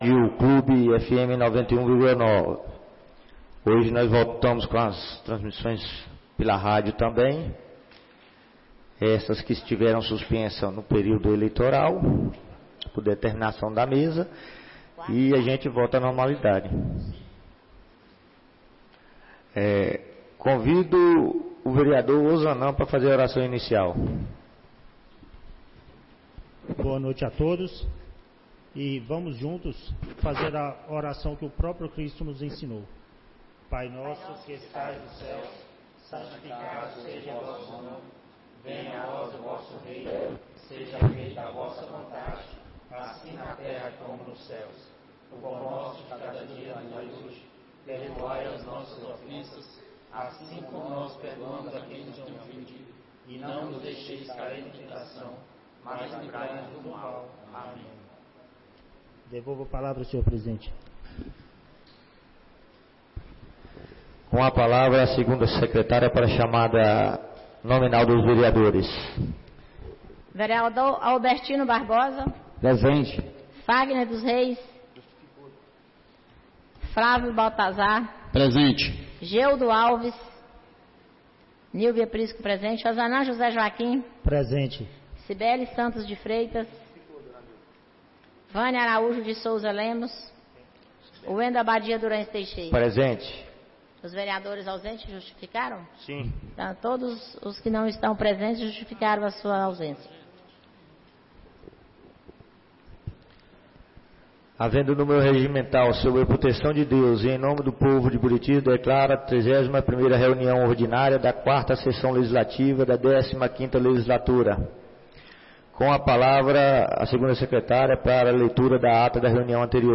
31ª Reunião Ordinária da 4ª Sessão Legislativa da 15ª Legislatura - 07-10-24